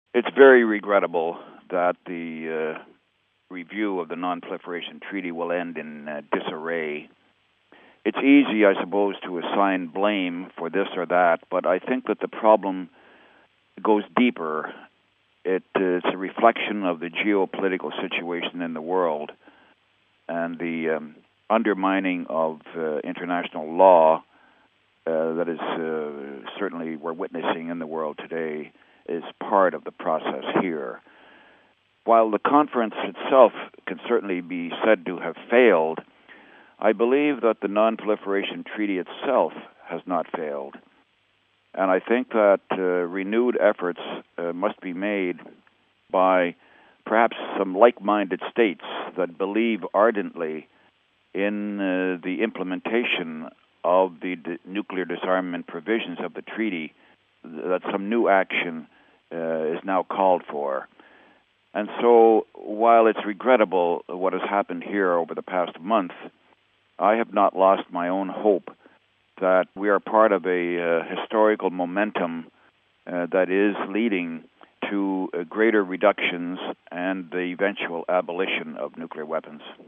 Canadian senator Douglas Roche is a member of the Holy See's delegation to the conference.